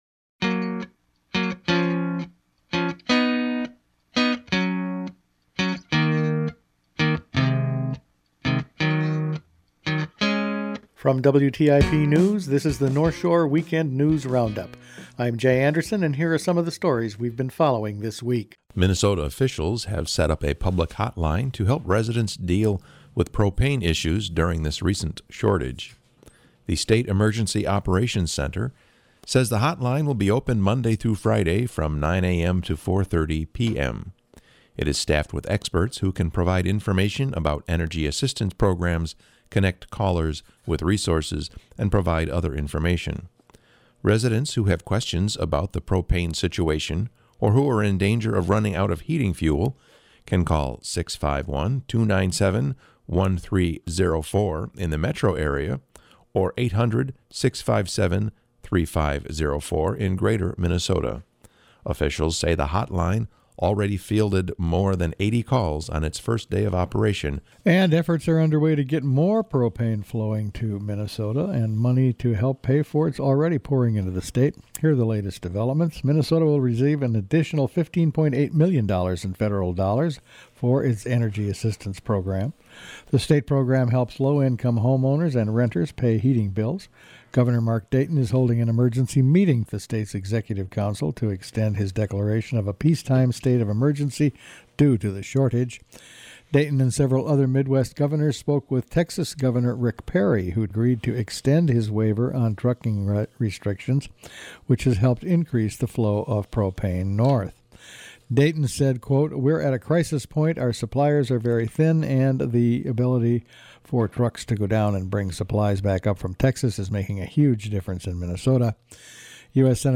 Each week the WTIP news staff puts together a roundup of the news over the past five days. The current propane crisis and mining issues…all in this week’s news.